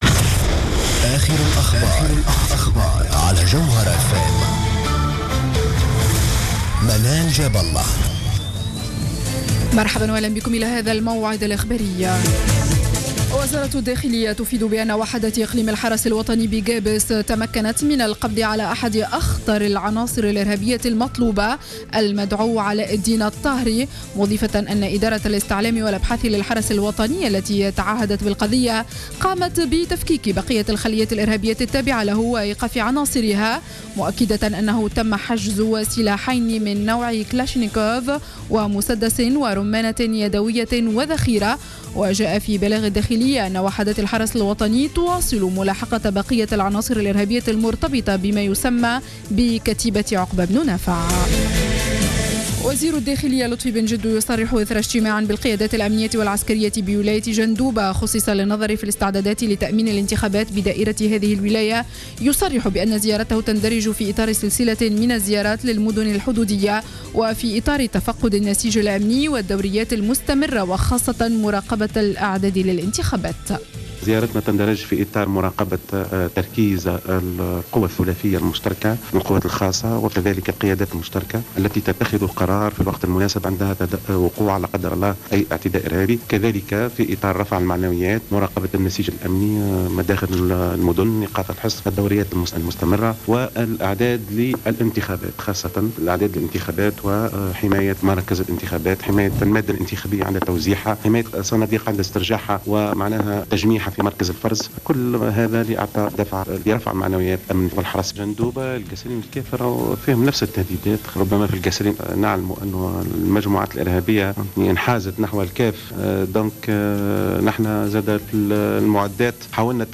نشرة أخبار منتصف الليل ليوم الجمعة 17-10-14